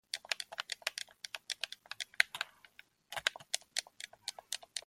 Game Controller Button Sound
game-controller-button-sound-43684.mp3